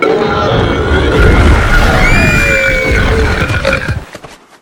oocburp2.ogg